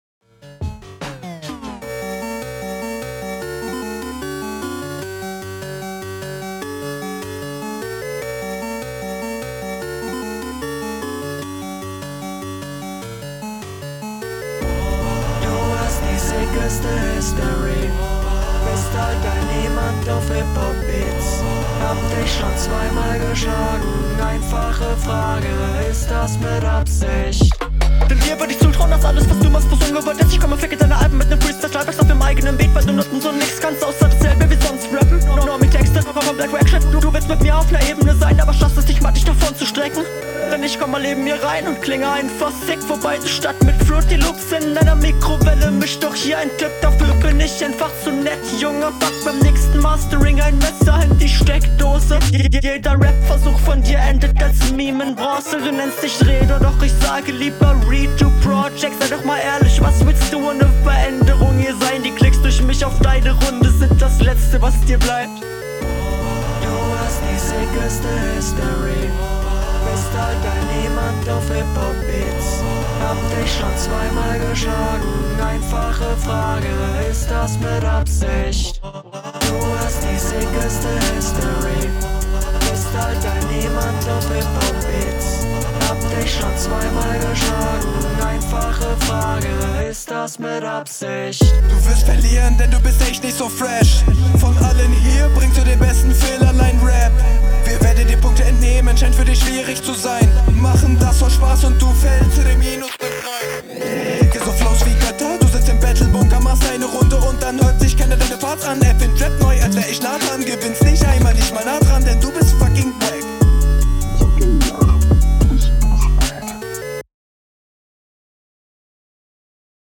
Komischer tetris beat aber sonst ganz gut. flow ganz gut
Sounddesign absolut insane